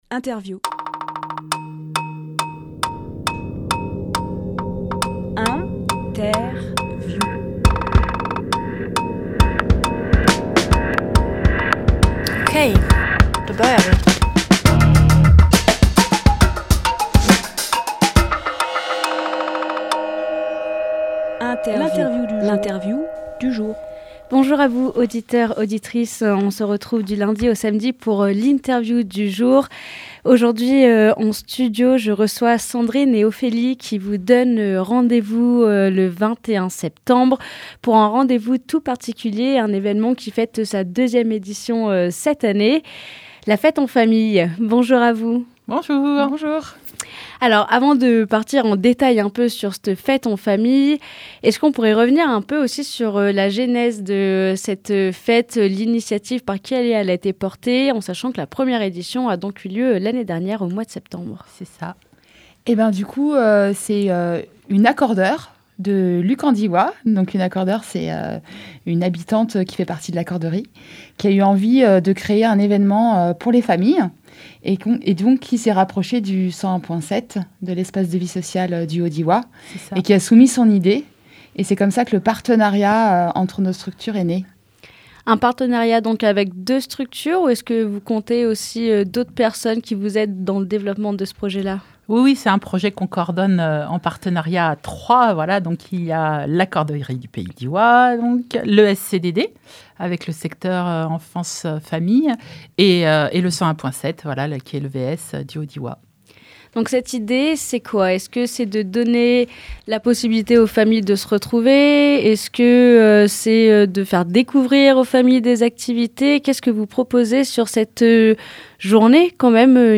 Emission - Interview , Le 101.7, Espace de Vie Sociale La Fête en Famille Publié le 14 septembre 2024 Partager sur…